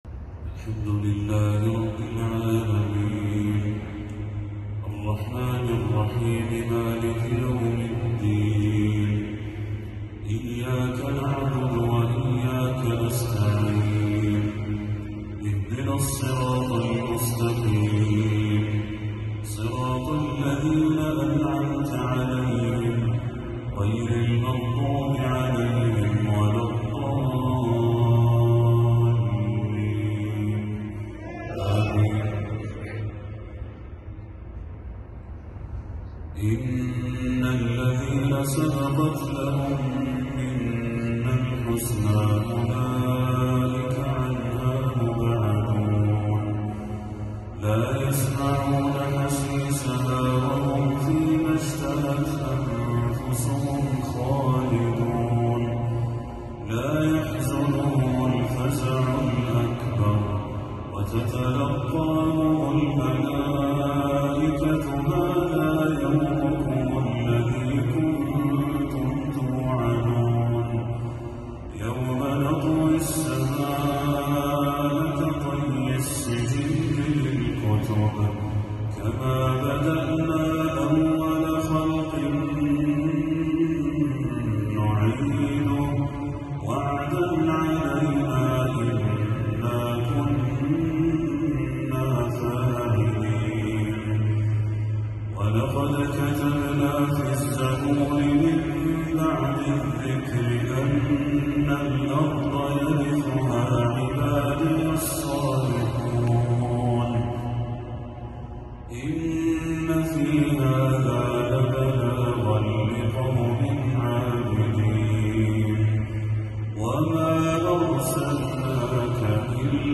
تلاوة عذبة لخواتيم سورة الأنبياء للشيخ بدر التركي | مغرب 12 ربيع الأول 1446هـ > 1446هـ > تلاوات الشيخ بدر التركي > المزيد - تلاوات الحرمين